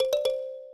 kalimba_cdc.ogg